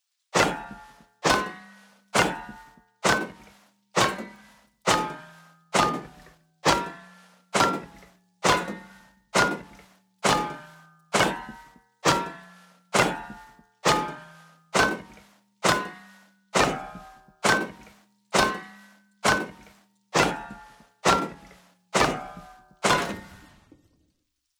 Stone Hatchet On Sheet Metal